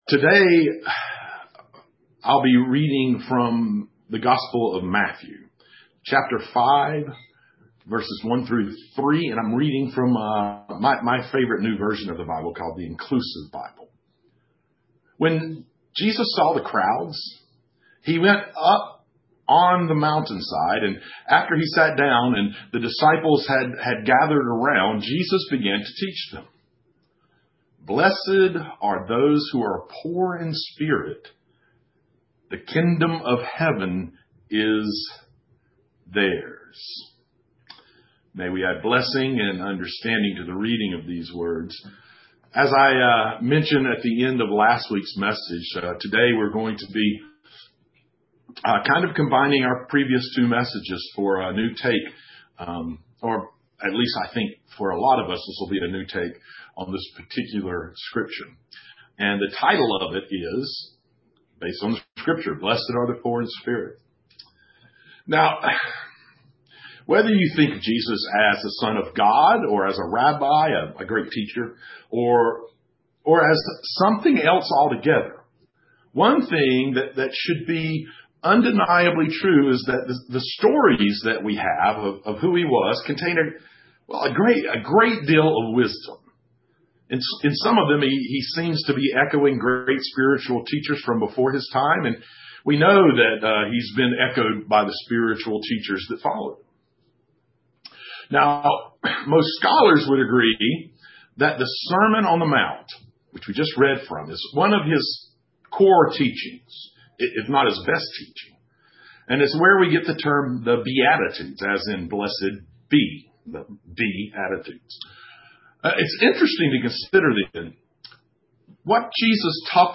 Matthew 5:1-3 (streamed via Zoom and Facebook)